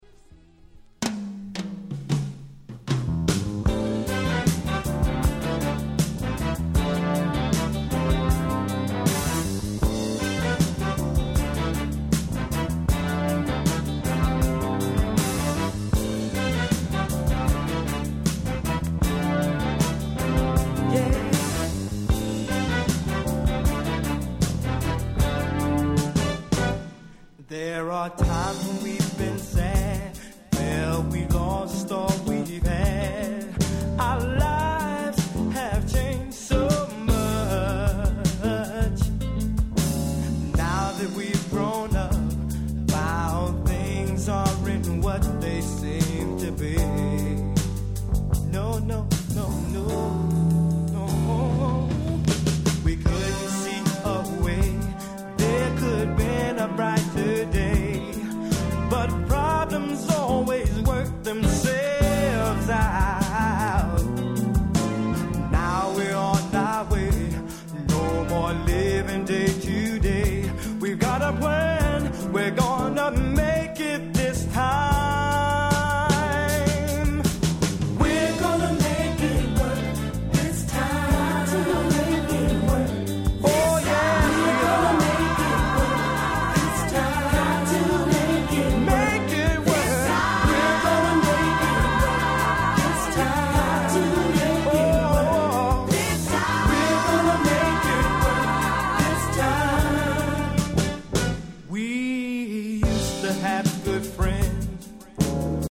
90' Nice Soul/R&B LP !!